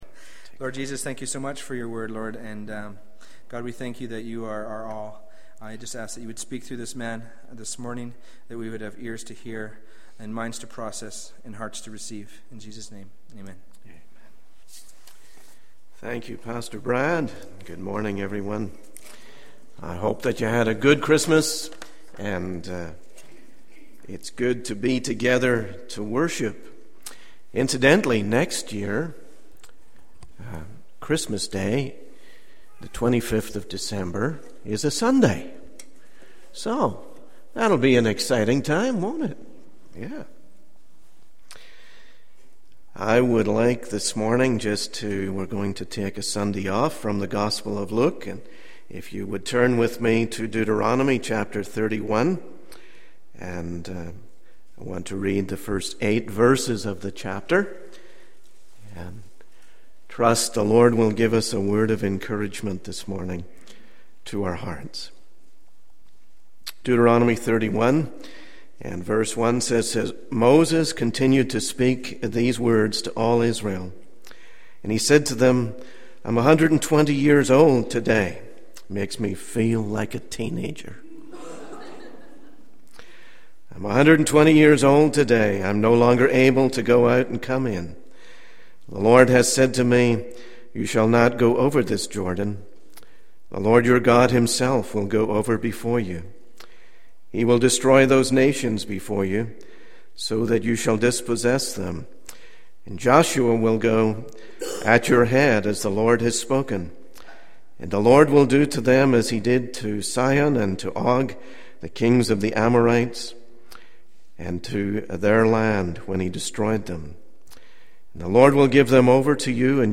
In this sermon, the speaker reflects on the faithfulness of God in the past year and encourages the congregation to look forward to the new year with hope. He emphasizes the promise that God goes before His people, providing comfort and guidance in uncertain times.